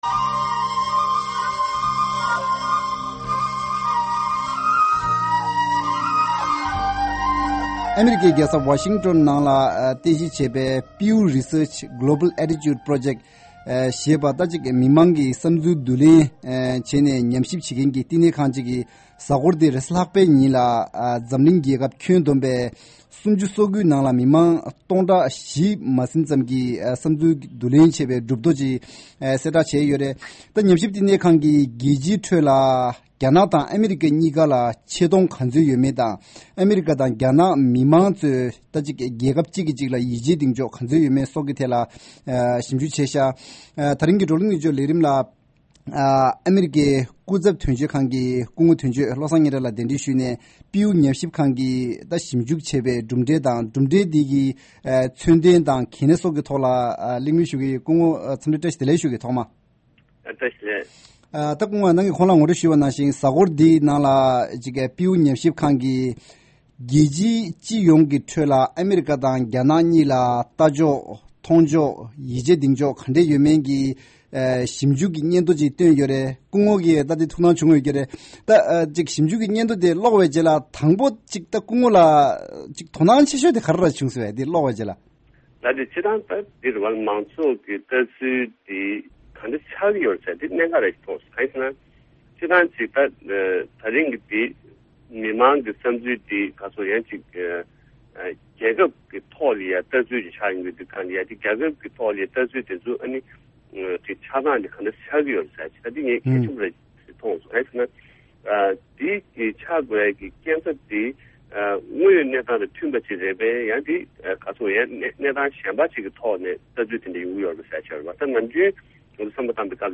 ལས་རིམ་གྱིས་ཨ་རིའི་ནེའུ་ཡོག་གྲོང་ཁྱེར་ནང་གནས་འཁོད་སྐུ་ཚབ་དོན་གཅོད་ཁང་གི་སྐུ་ངོ་བློ་བཟང་སྙན་གྲགས་ལགས་སུ་ཞིབ་འཇུག་གི་གྲུབ་ཐོ་དེའི་དོན་སྙིང་ དང་མཚོན་འདོན་ཐད་གླེང་མོལ་ཞུ་གི་རེད།